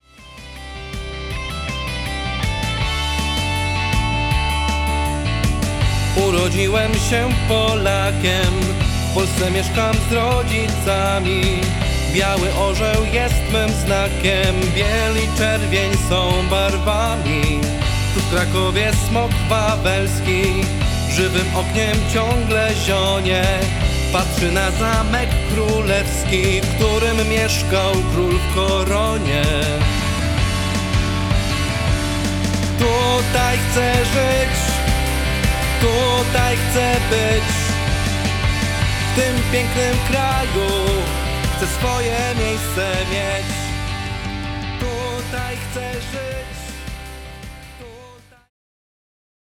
Energiczna piosenka patriotyczna.